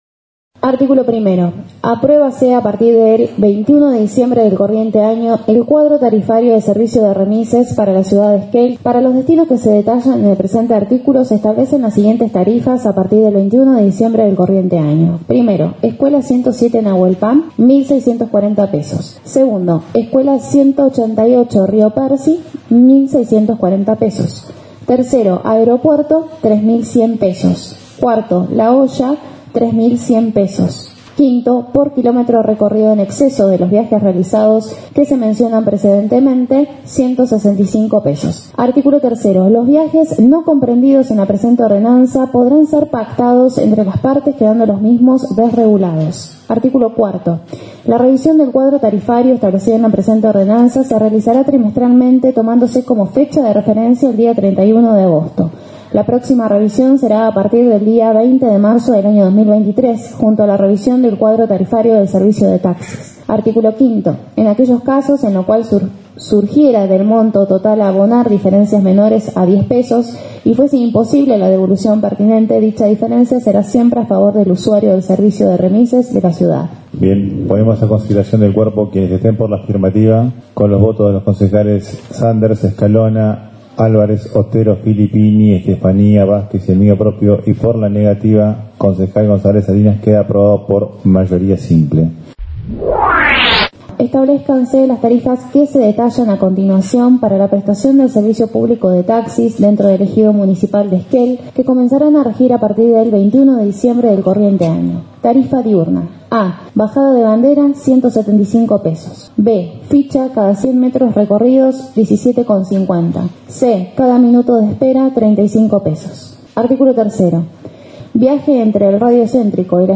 En el marco de la última sesión ordinaria del Concejo Deliberante, los ediles aprobaron por mayoría (con el voto negativo del Concejal Gonzales Salinas) el aumento de las tarifas de taxis y remises.
18°-sesion-hcd-presupuesto-4-aumento-taxis.mp3